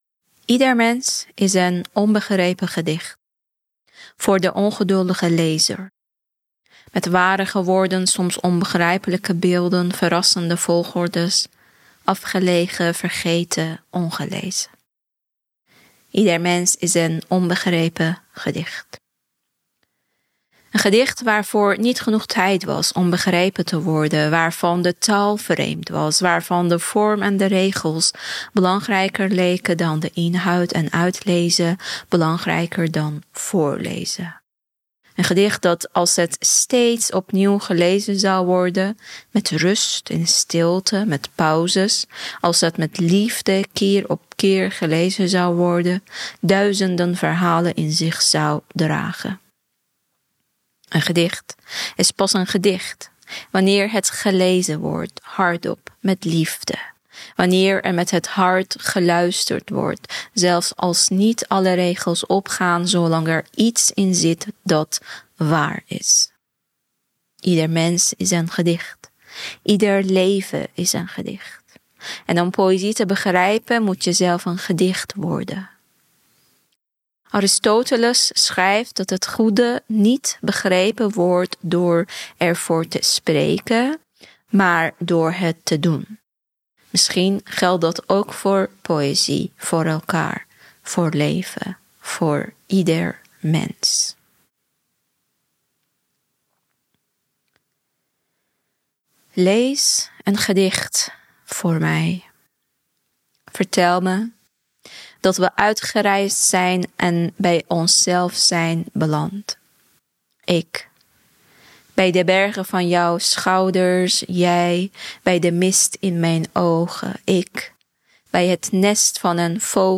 Deze vragen onderzoeken we door het voeren van uiteenlopende gesprekken.